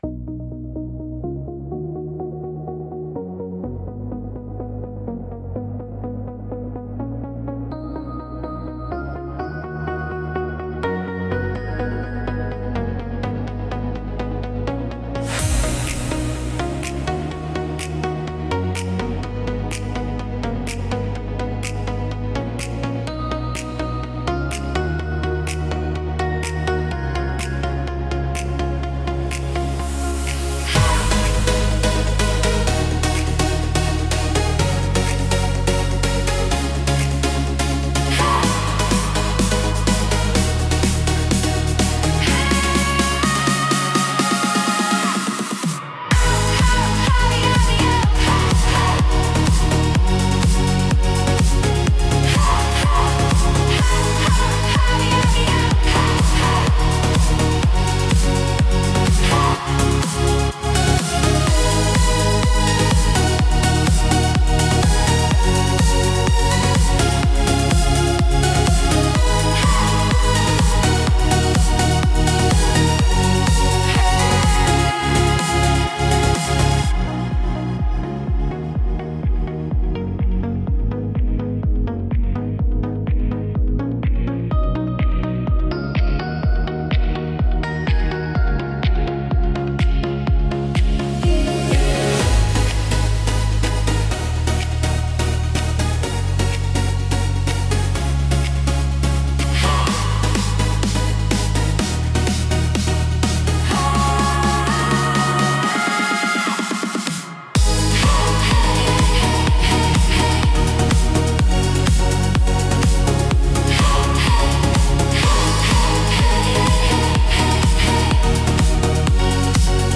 聴く人の気分を高め、集中力とパワーを引き出すダイナミックなジャンルです。